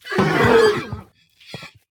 CosmicRageSounds / ogg / general / combat / creatures / horse / he / die1.ogg